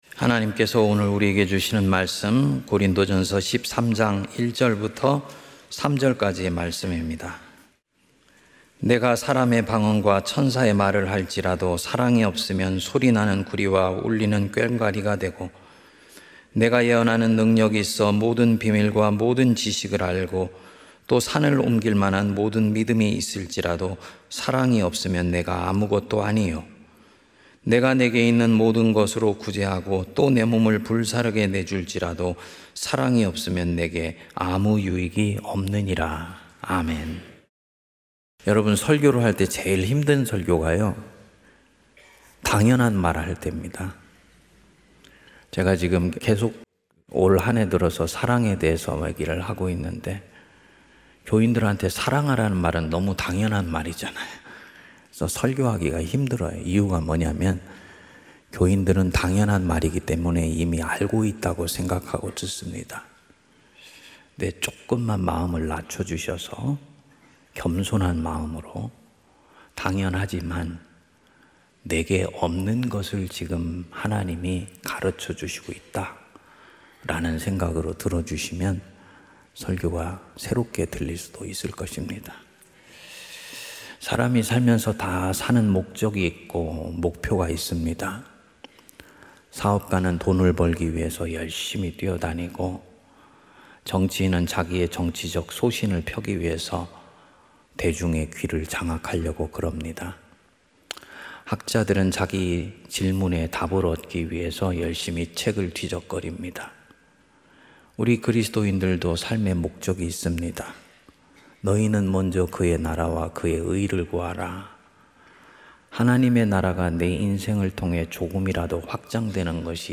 새문안교회 주일설교